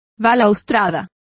Complete with pronunciation of the translation of balustrade.